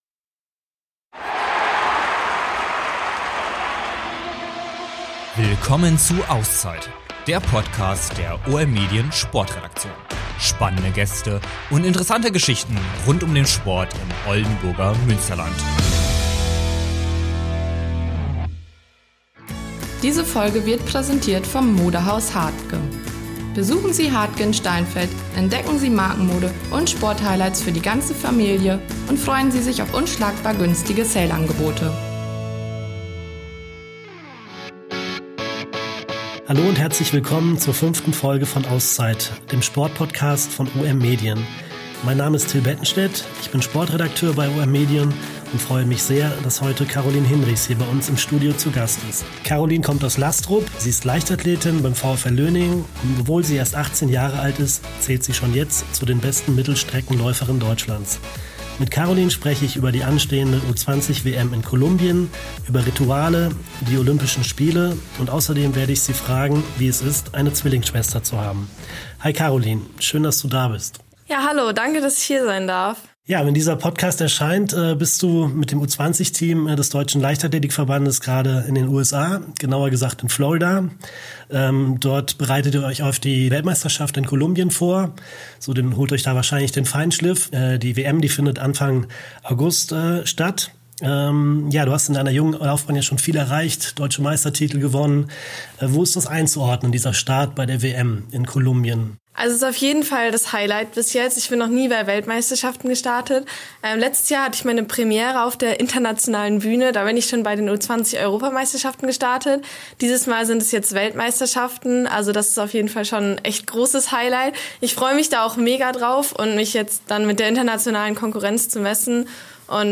spricht die Leichtathletin